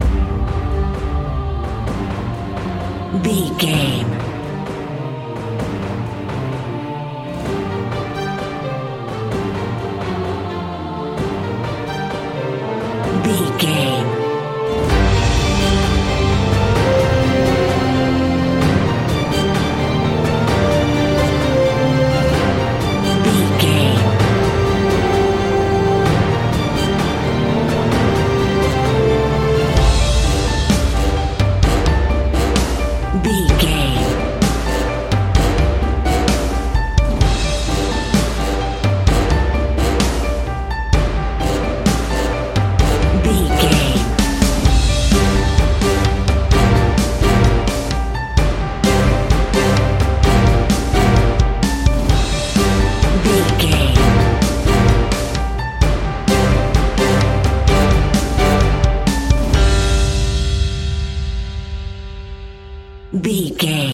Action Cinema Music Theme.
In-crescendo
Aeolian/Minor
tension
ominous
dark
dramatic
haunting
eerie
strings
brass
orchestra
drums
percussion
piano
synth
pads